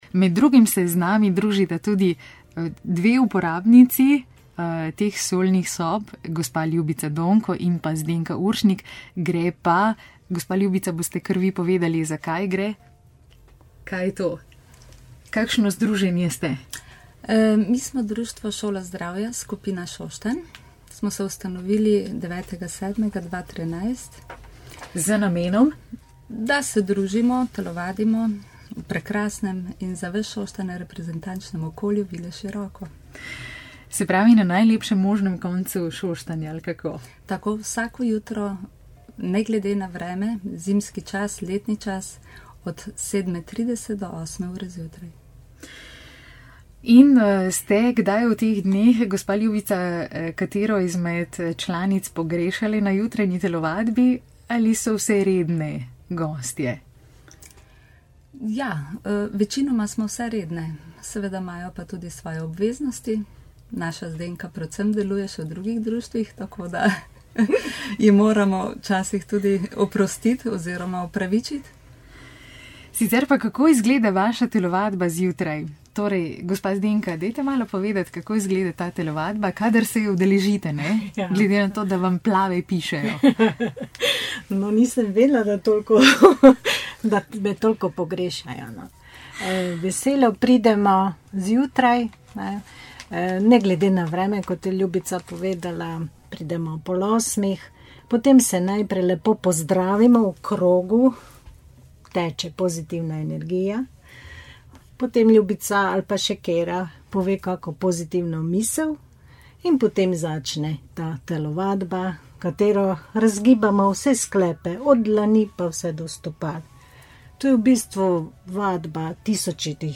intervju-ra-velenje.mp3